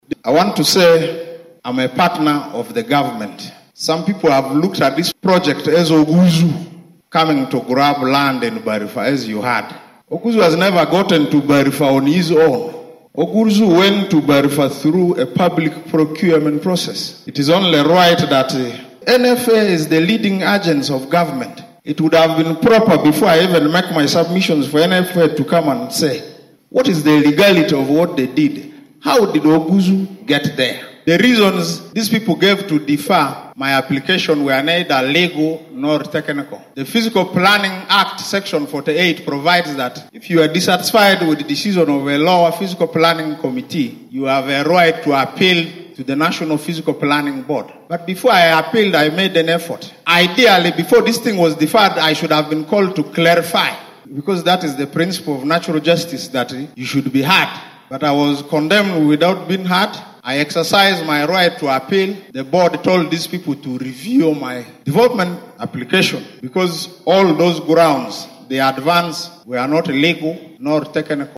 The disagreement came to a head during a stakeholders meeting on January 30th, where tensions escalated, leading to a premature end.
Dennis Lee Oguzu, the driving force behind the project, expressed his disappointment during the meeting.